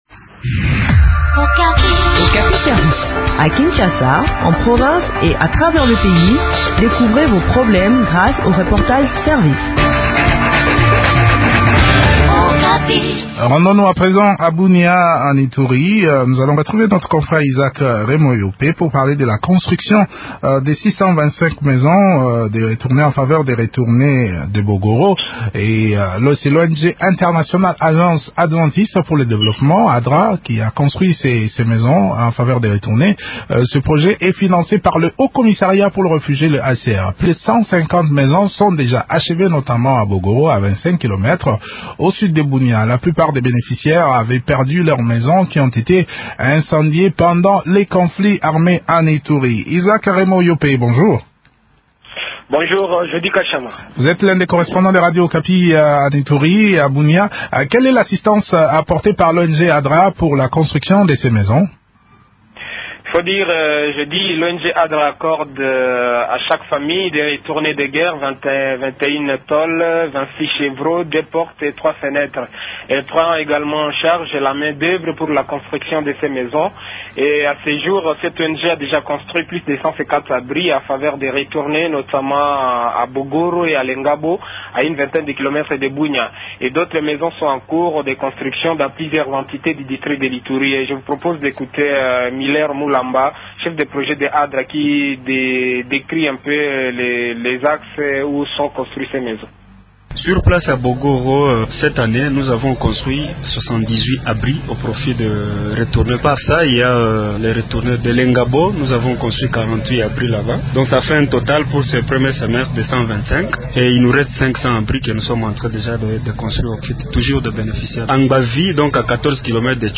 Bunia : construction de 625 maisons en faveur des retournés de guerre | Radio Okapi